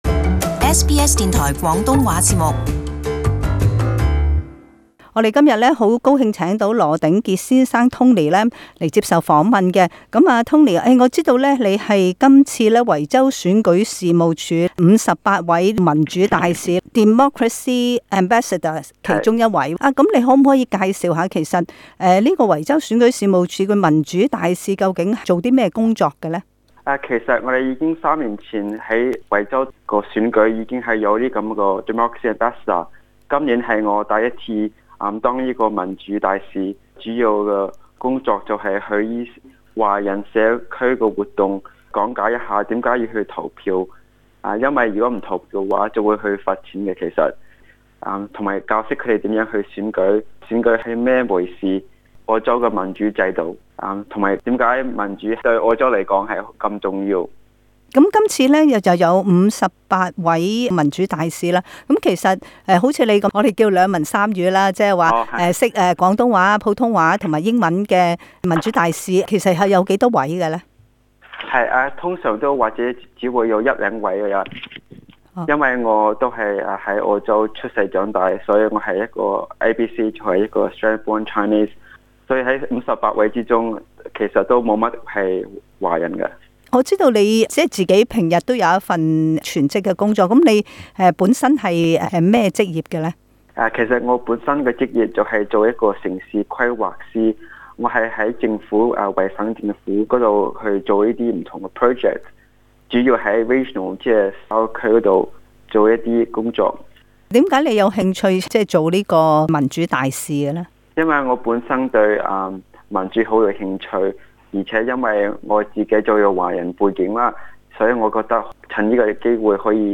【社區專訪】維州大選民主大使